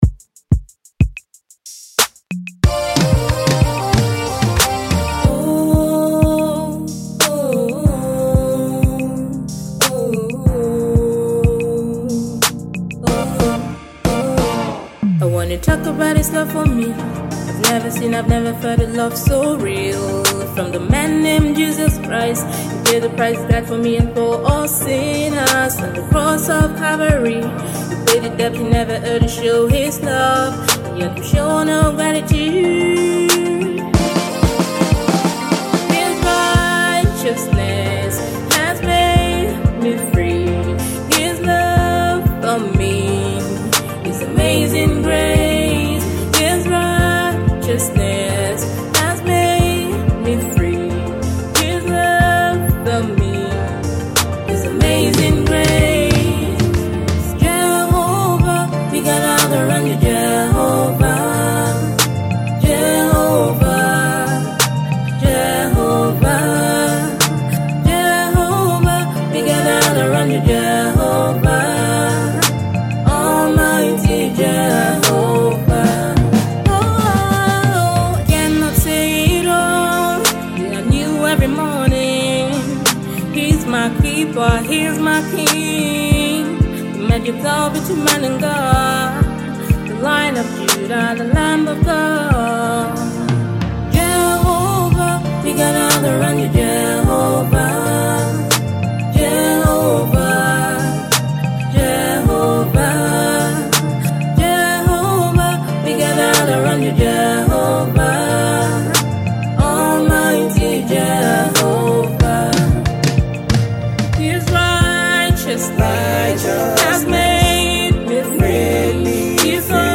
heartfelt worship song